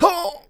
hurt4.wav